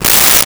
Paper Tear 06
Paper Tear 06.wav